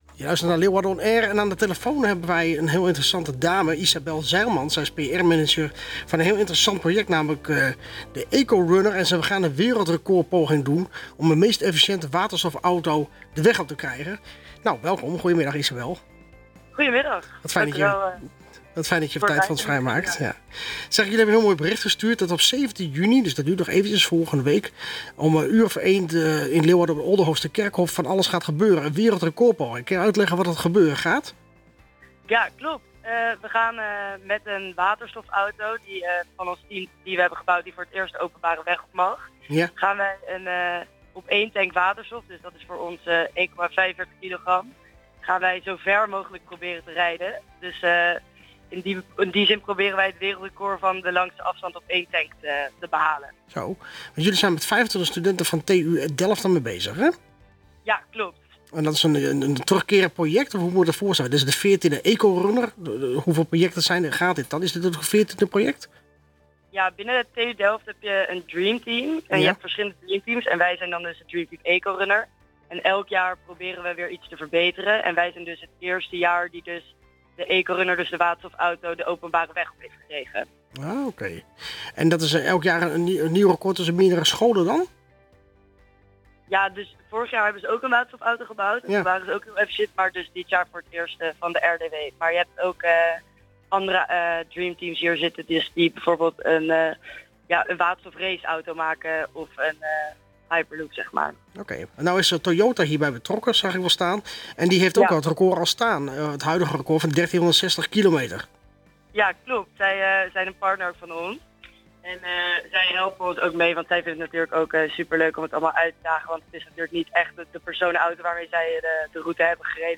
Meer informatie hierover was dinsdag te beluisteren in het radioprogramma Leeuwarden On Air tussen 16:00 en 17:00.